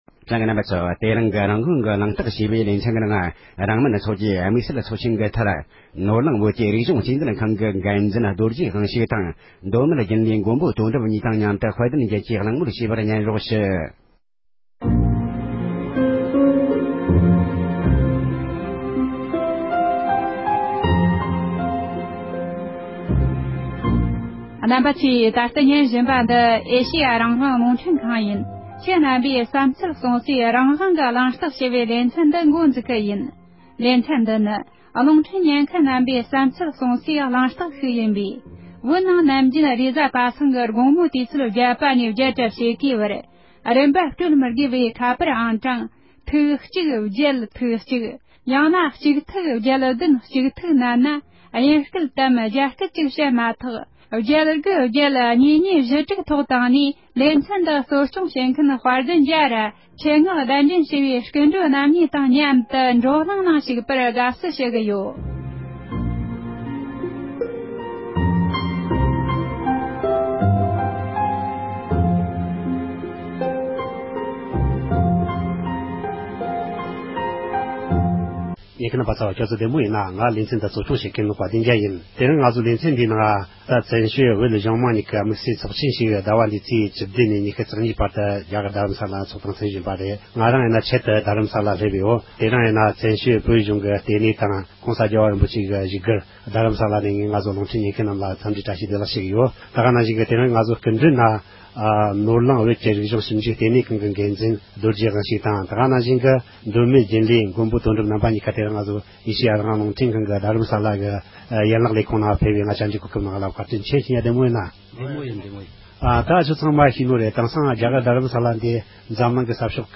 བོད་མི་ཁག་ལ་དམིཌ་བསལ་ཚོཌ་ཆེན་སྐོར་བཀའ་འདྲི་ཞུས་པ།